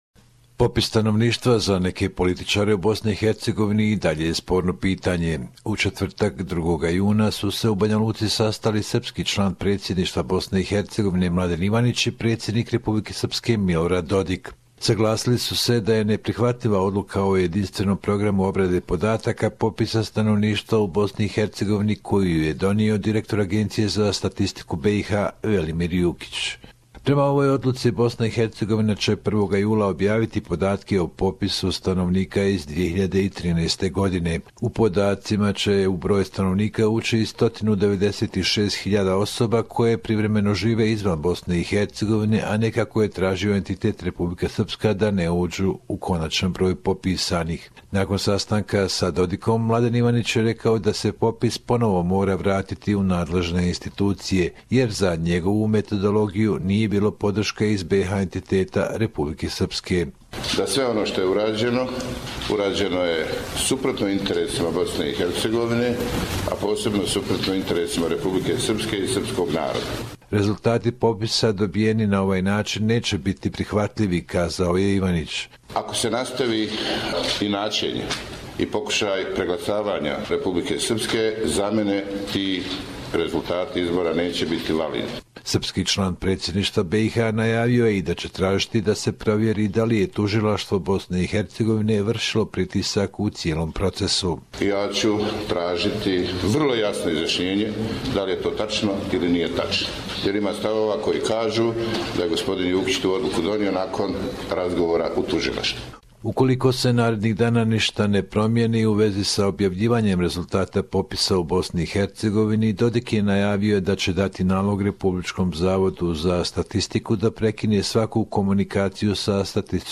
Report from Bosnia and HerzegovinaJune 3